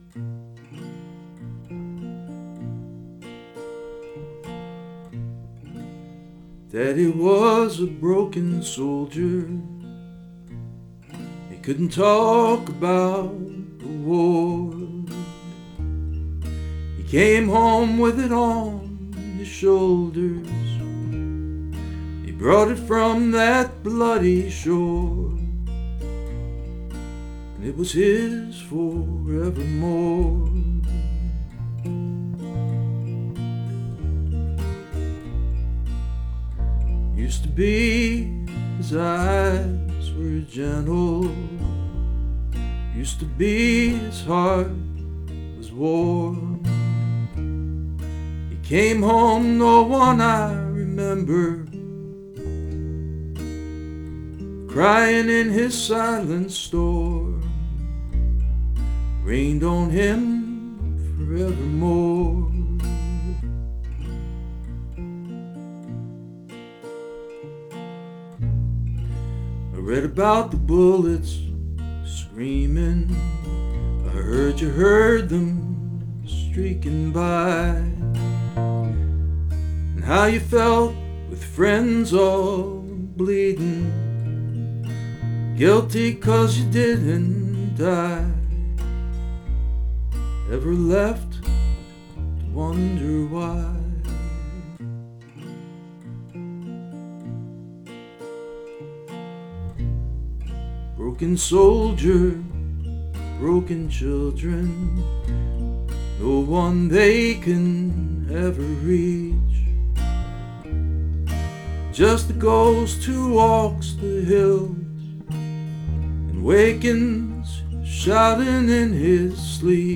I never got around to making a better-recorded take of this song, but the spirit’s there.